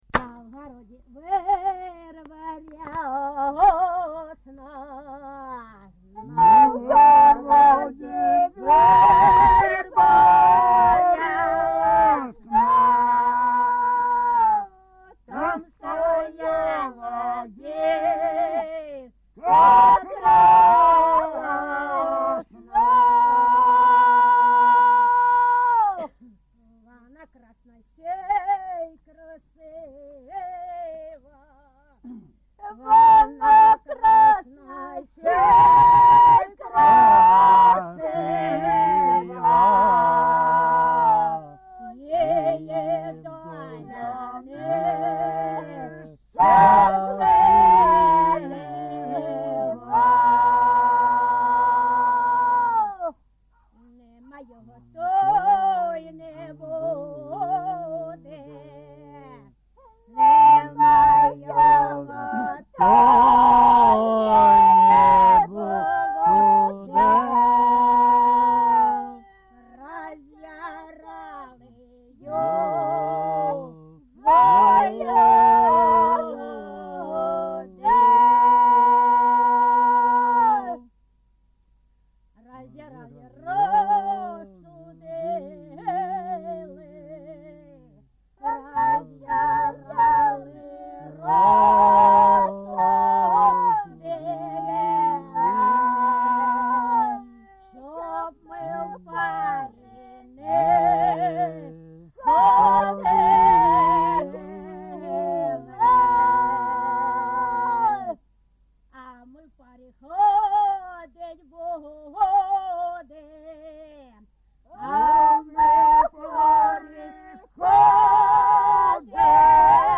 ЖанрПісні з особистого та родинного життя
Місце записум. Дружківка, Краматорський район, Донецька обл., Україна, Слобожанщина